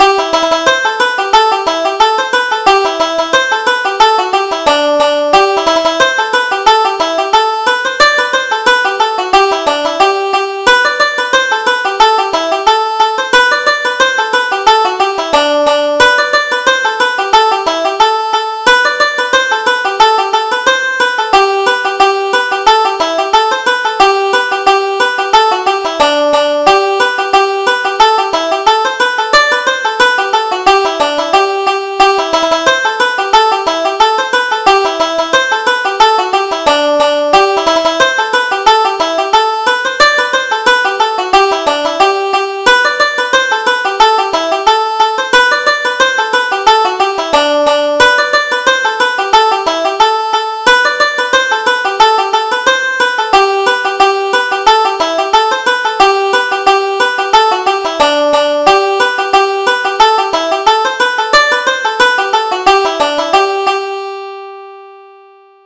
Reel: Tear the Calico